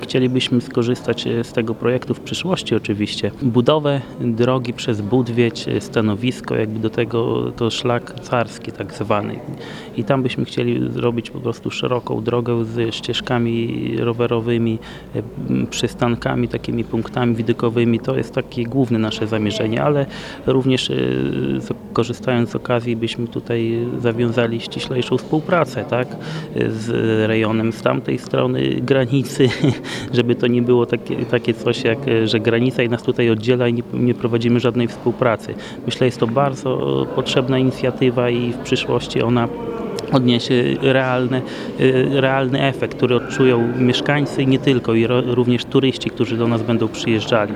Jak mówi Robert Bagiński, zastępca wójta Gib, podstawą są drogi. Samorządowiec w swojej gminie widzi potrzebę odbudowy drogi przez Budwieć.